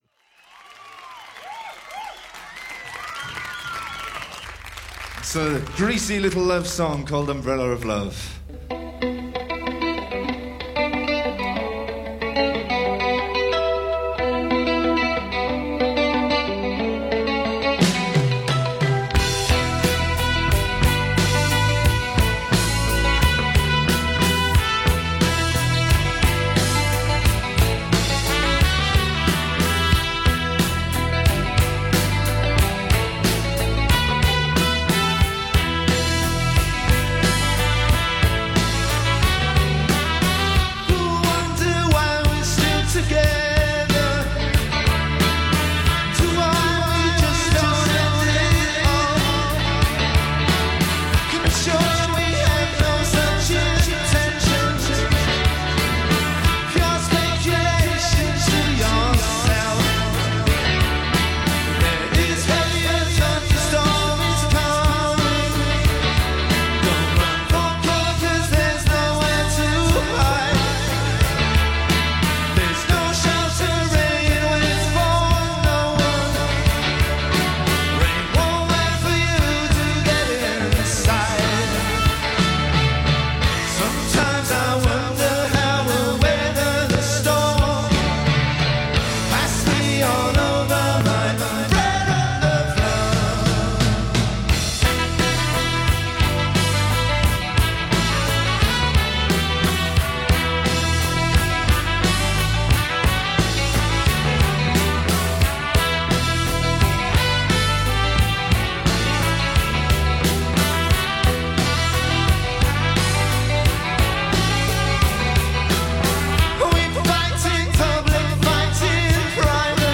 live from Paris Theatre.
Welsh short-lived pop band